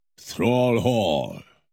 thrall hall wow Meme Sound Effect
thrall hall wow.mp3